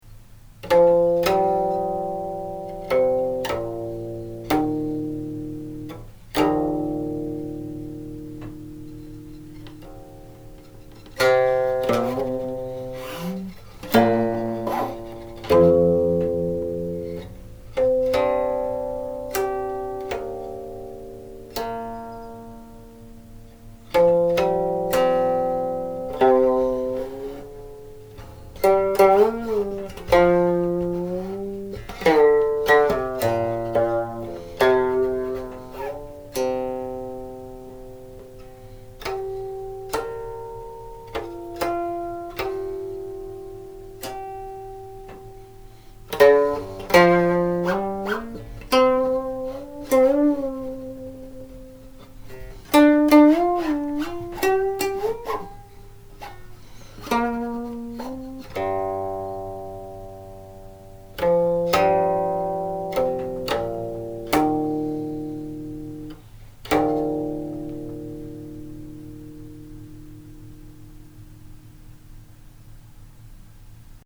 As stated above, in addition to being a qin song in its own right, Qingping Yue is the name of a cipai: a Chinese poetic structure.
One section; a mostly syllabic setting by "Lazy Monk Toko" of lyrics attributed to "Master of Wind and Moon Sun Cangqiu"
00.00 Prelude made from the closing harmonics